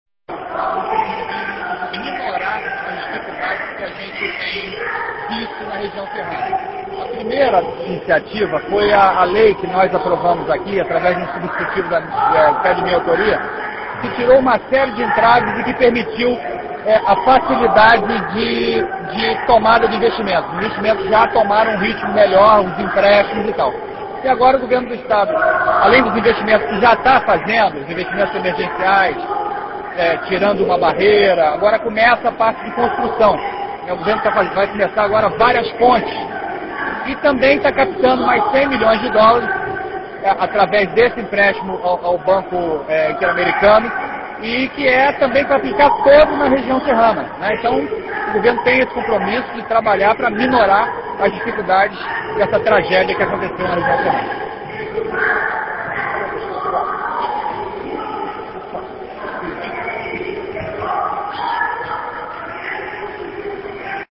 Áudio – André Corrêa fala sobre o empréstimo autorizado de U$ 100 milhões para região serrana